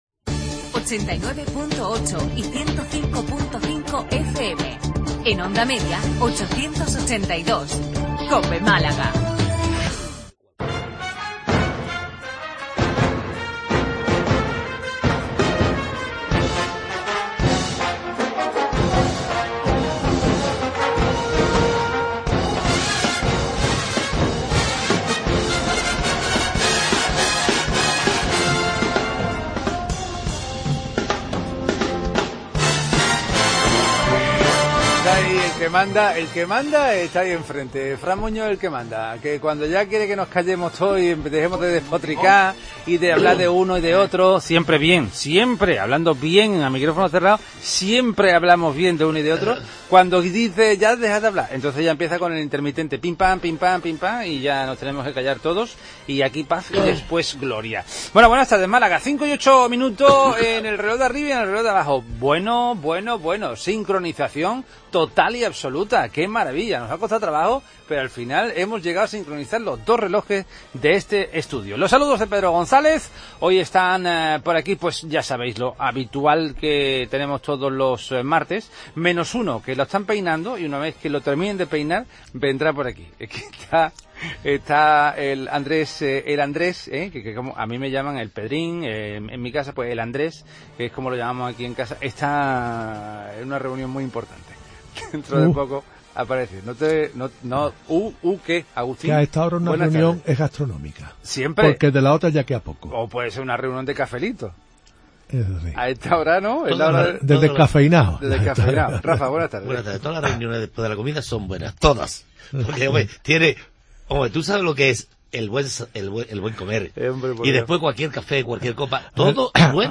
AUDIO: Tertulia de los temas que interesan a Málaga y los Malagueños.